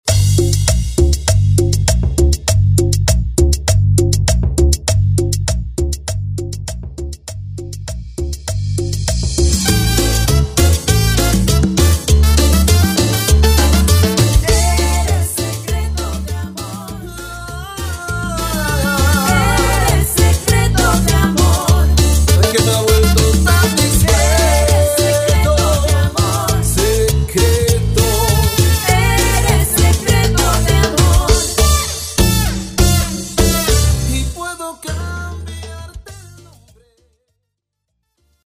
DJ